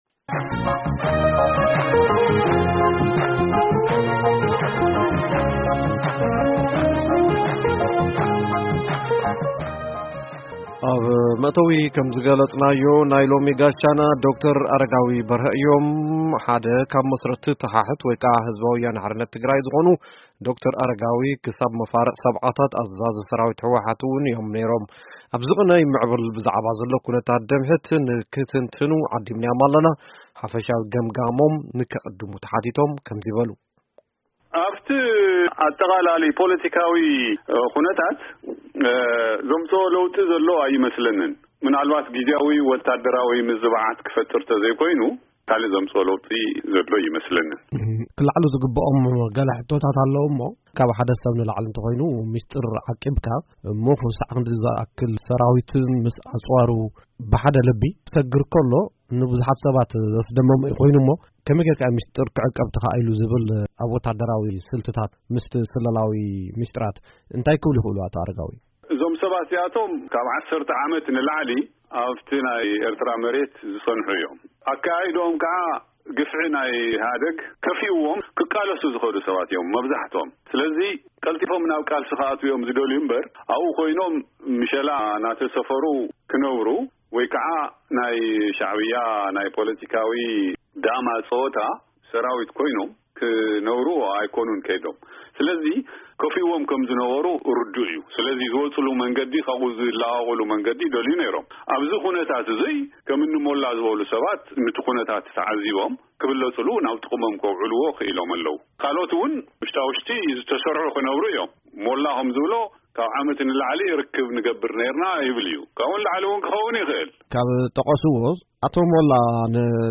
ሙሉእ ቃለ-መጠይቅ